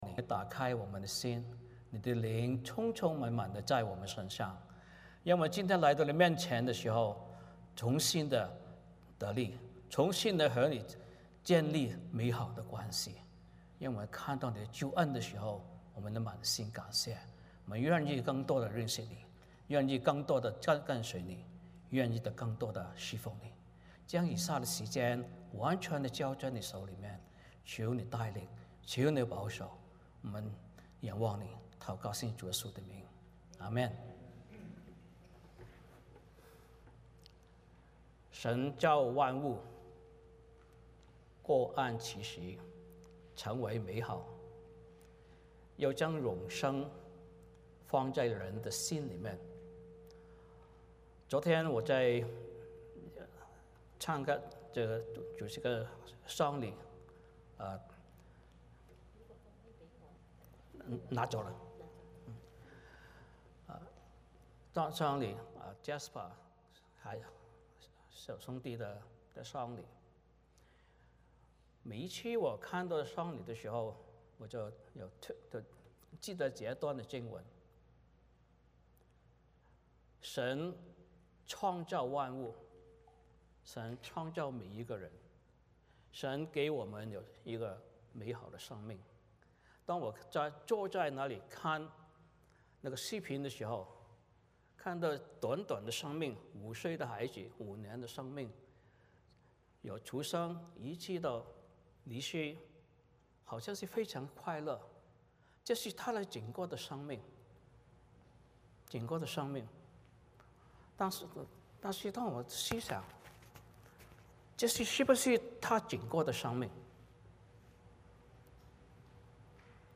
欢迎大家加入我们国语主日崇拜。